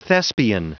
Prononciation du mot thespian en anglais (fichier audio)
Prononciation du mot : thespian